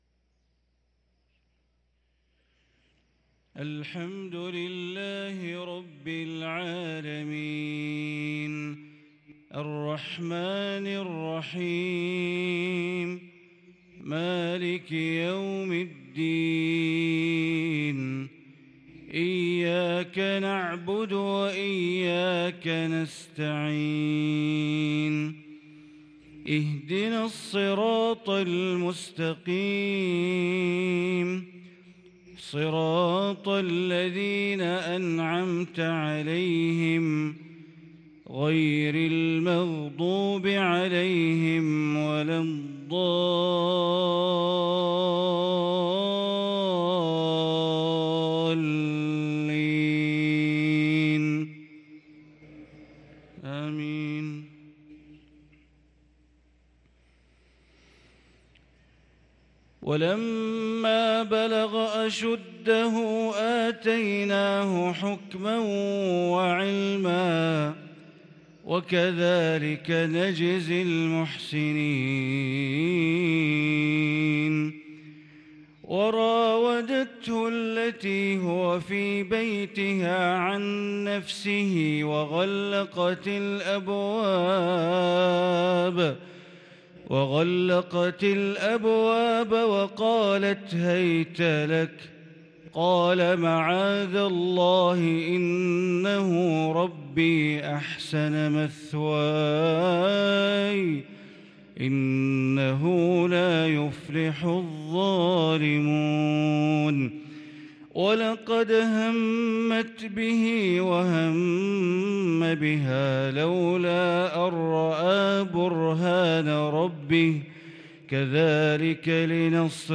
صلاة الفجر للقارئ بندر بليلة 14 ربيع الأول 1444 هـ